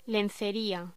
Locución: Lencería